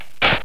دانلود آهنگ کارتون 37 از افکت صوتی اشیاء
جلوه های صوتی